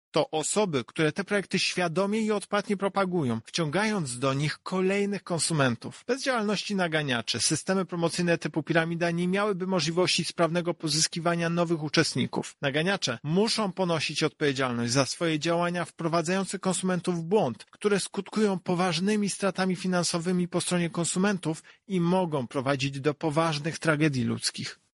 To właśnie naganiacze są istotnym elementem funkcjonowania tego systemu – mówi prezes UOKiK Tomasz Chróstny: